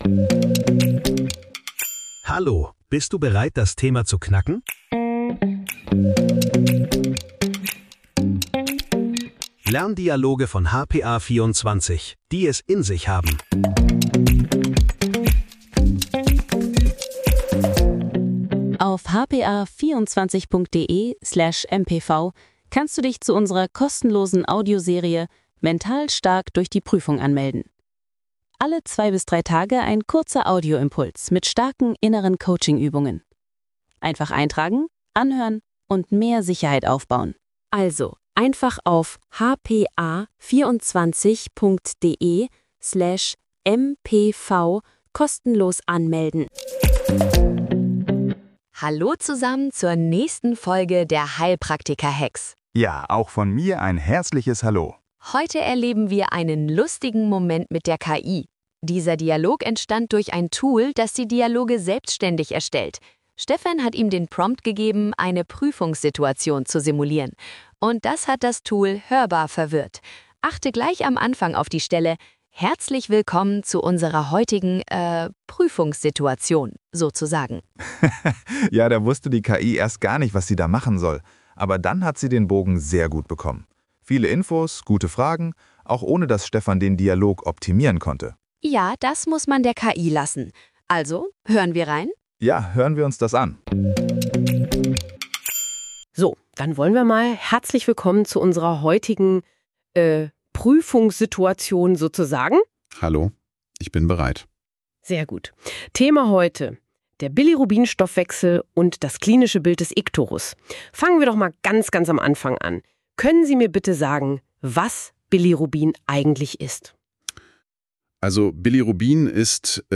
Auch wenn die KI zu Beginn kurz über ihre eigenen Rollen stolpert, liefert sie danach ein fachlich dichtes Training ab...